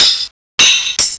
PERC LOOP4-L.wav